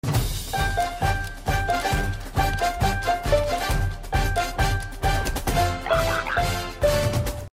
Piano Mp3 Sound Effect